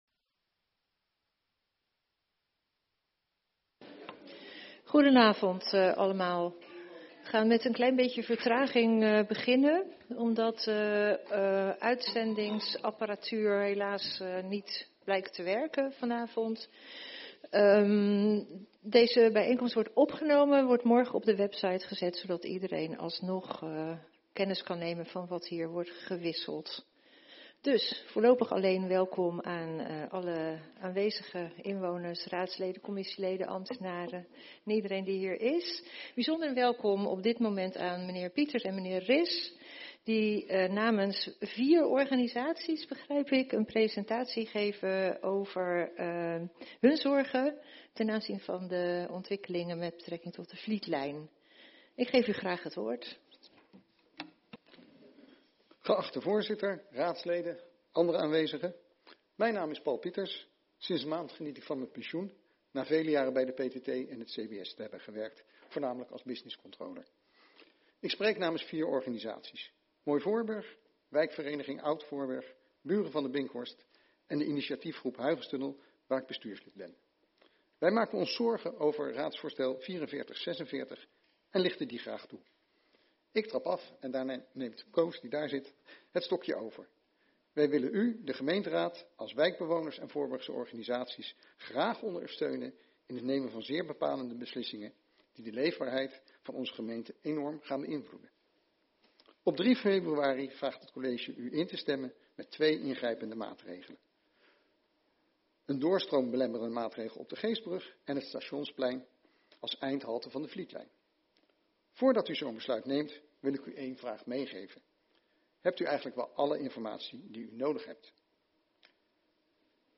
Agenda Leidschendam - Beeldvorming raadszaal Presentatie Vlietlijn van inwoners dinsdag 13 januari 2026 19:00 - 19:30 - iBabs Publieksportaal
Hier treft u de opname van alleen het geluid van beide bijeenkomsten Beeldvorming, te weten: - de bijeenkomst met de presentatie door de inwoners vanaf 19:00 uur; en - de bijeenkomst met de presentatie namens het college vanaf 19:30 uur; in het geluidsbestand start die bijeenkomst op 35 minuten en 55 seconden.